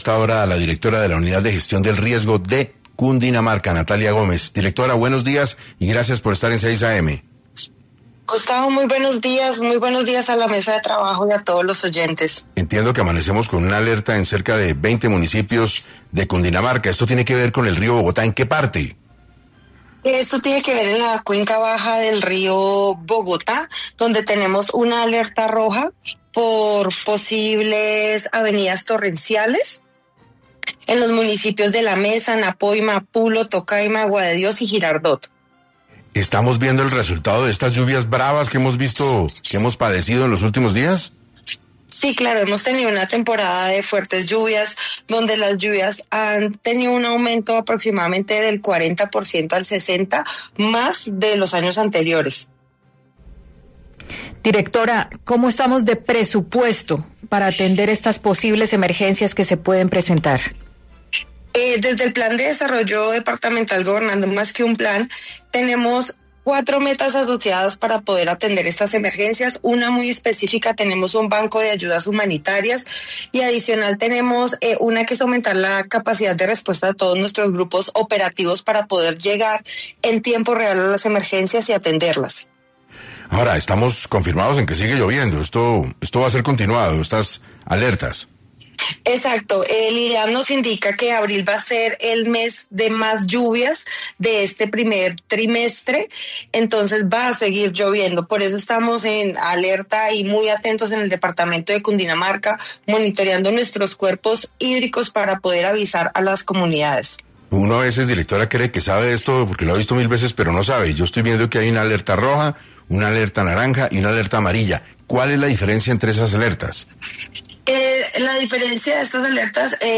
Es por eso que en una entrevista para 6AM, la directora de la Unidad Gestión Del Riesgo de Cundinamarca, Natalia Gómez, nos explica cómo se desarrolla la situación y cómo deberíamos atenderla.